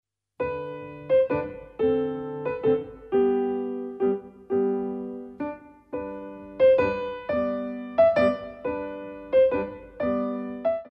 36. Sicilienne